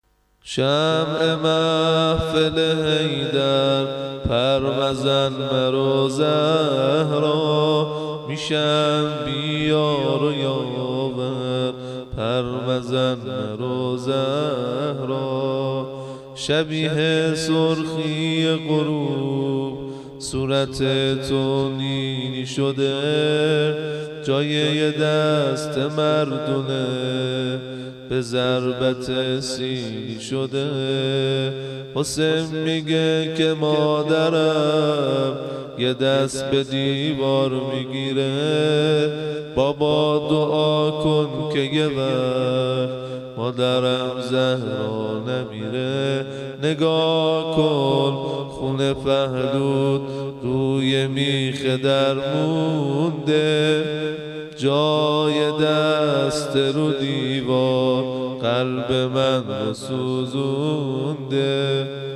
شور ( سبک: پرستوی غریبم)